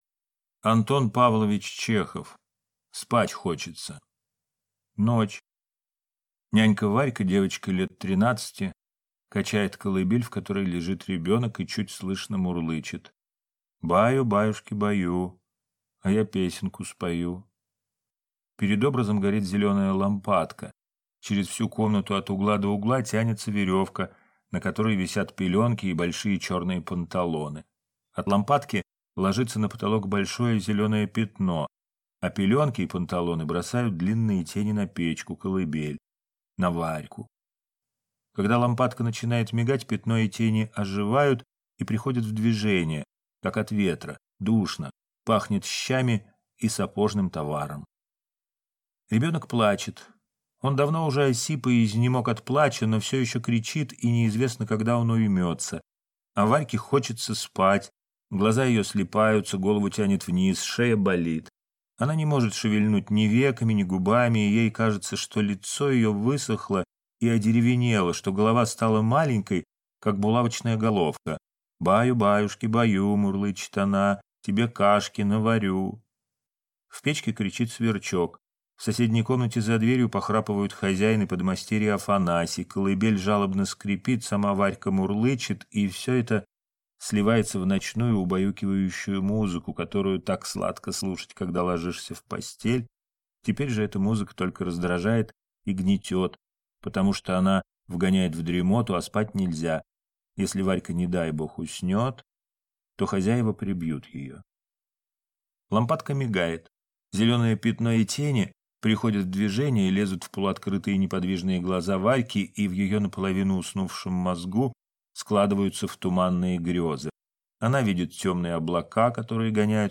Аудиокнига Спать хочется | Библиотека аудиокниг